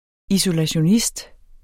Udtale [ isolaɕoˈnisd ]